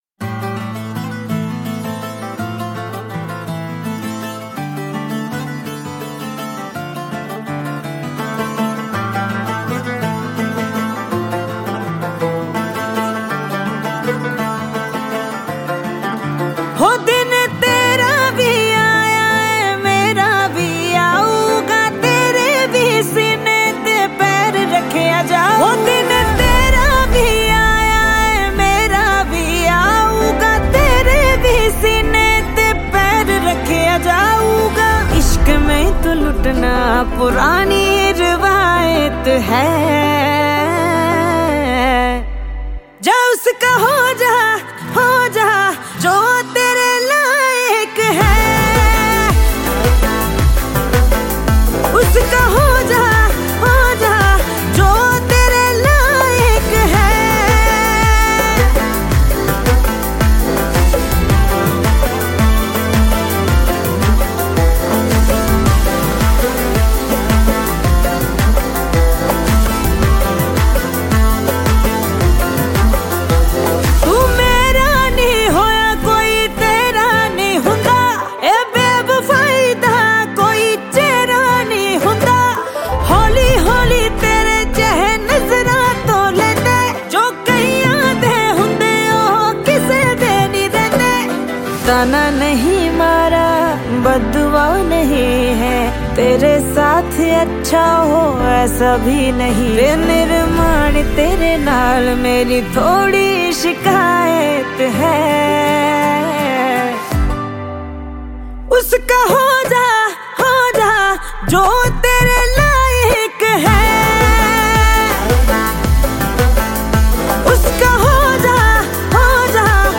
Hindi